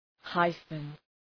{‘haıfən}